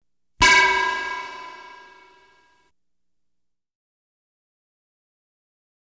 Light Beam Hit Sound Effect
Download a high-quality light beam hit sound effect.
light-beam-hit-4.wav